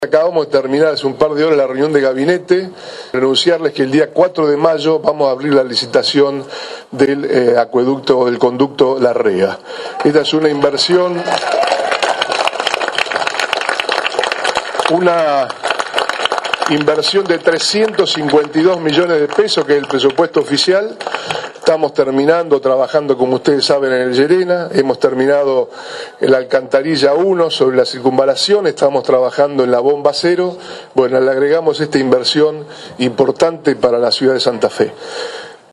“El 4 de mayo vamos a abrir los sobres con las ofertas. Es una inversión de 352 millones de pesos”, precisó el gobernador, durante el acto de entrega de 98 viviendas para docentes en la capital provincial, en el marco del Programa Habitacional Docente.